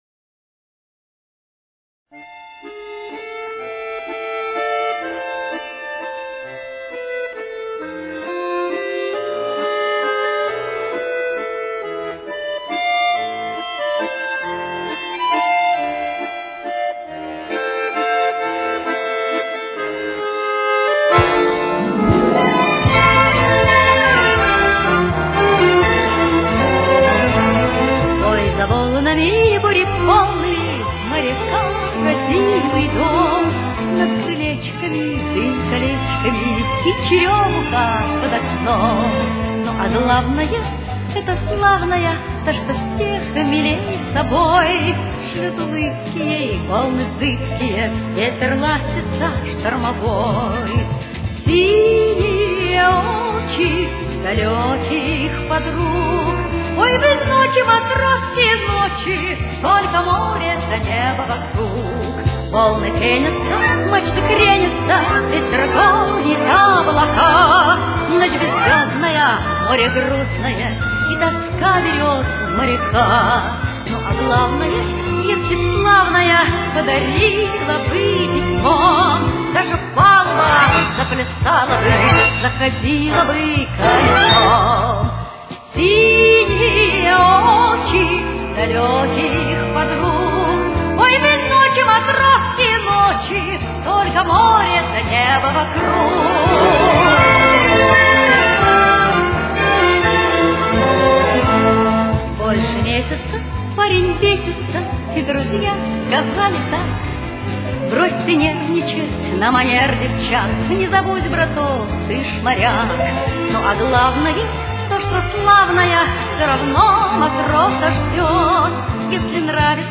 с очень низким качеством (16 – 32 кБит/с)
Ля минор. Темп: 260.